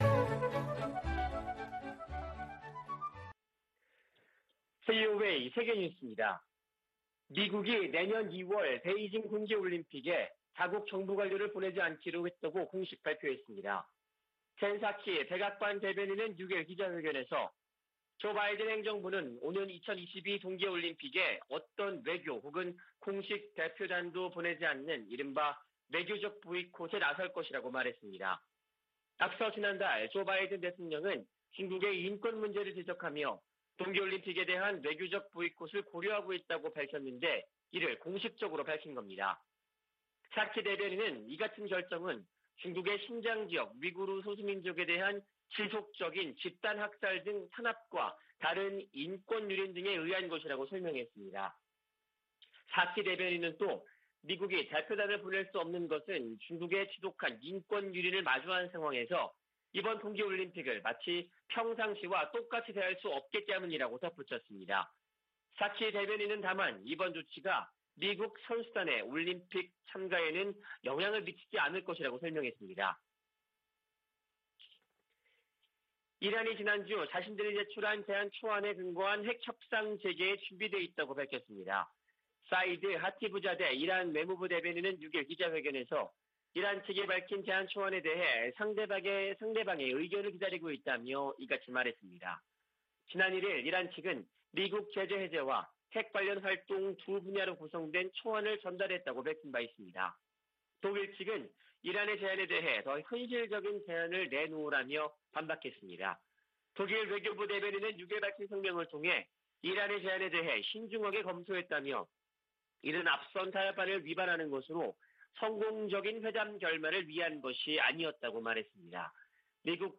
VOA 한국어 아침 뉴스 프로그램 '워싱턴 뉴스 광장' 2021년 12월 7일 방송입니다. 중국이 한반도 종전선언 추진에 지지 의사를 밝혔지만 북한은 연일 미국을 비난하며 냉담한 태도를 보이고 있습니다. 유엔이 올해에 이어 내년에도 북한을 인도지원 대상국에서 제외했다고 확인했습니다. 미국 유권자 42%는 조 바이든 행정부 출범 이후 미북 관계가 악화한 것으로 생각한다는 조사 결과가 나왔습니다.